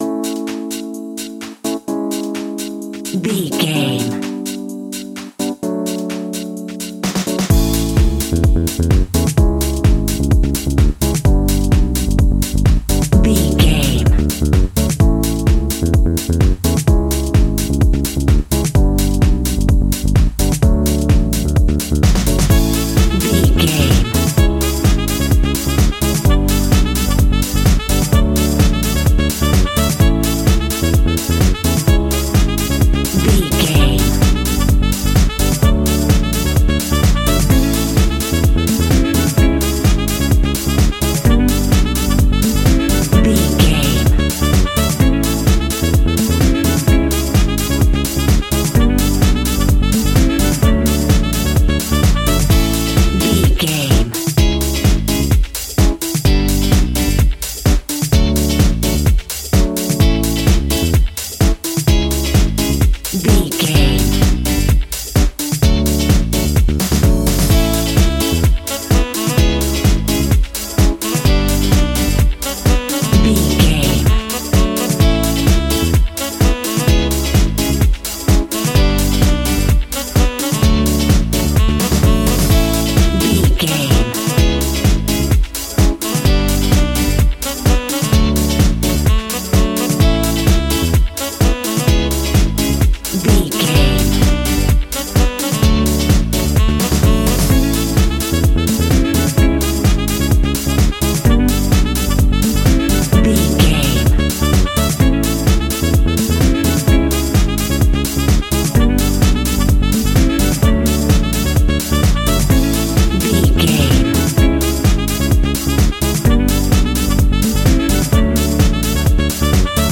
royalty free music
Ionian/Major
groovy
uplifting
bouncy
cheerful/happy
electric guitar
horns
drums
bass guitar
saxophone
disco
upbeat
wah clavinet
synth bass